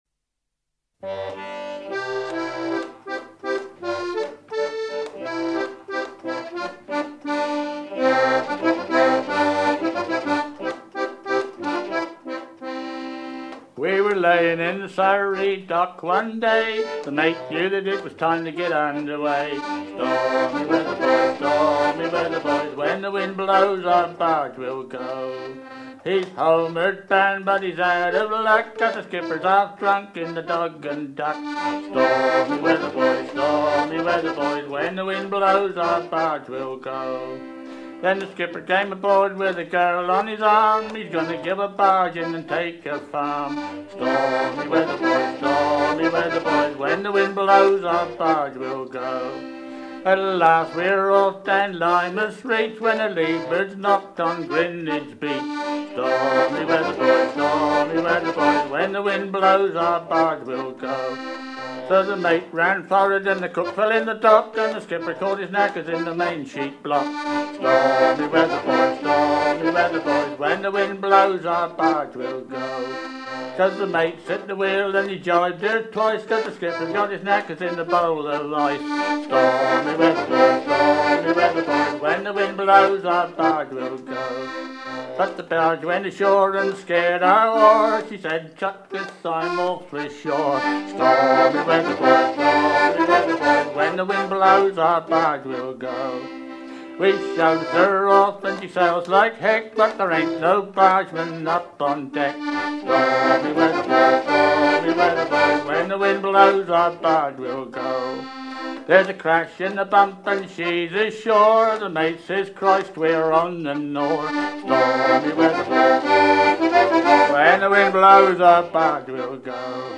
chanson humoristique propre aux marins des barges de la Tamise
Pièce musicale éditée